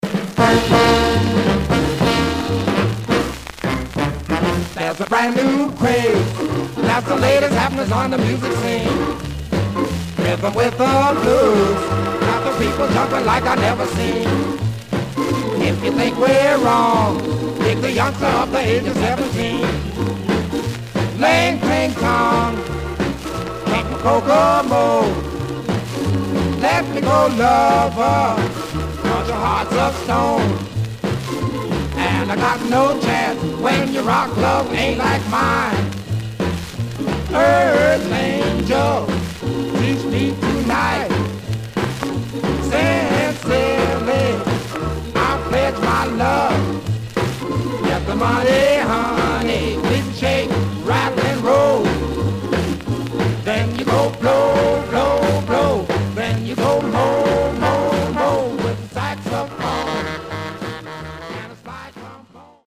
Surface noise/wear
Mono
Jazz